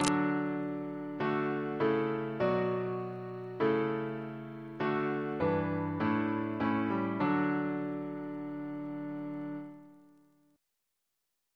Single chant in D Composer: John Harrison (1808-1871) Reference psalters: ACB: 26; ACP: 135; CWP: 15; PP/SNCB: 85; RSCM: 181